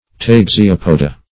Search Result for " taxeopoda" : The Collaborative International Dictionary of English v.0.48: Taxeopoda \Tax`e*op"o*da\, n. pl.
taxeopoda.mp3